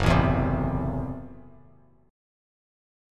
Gbm7b5 chord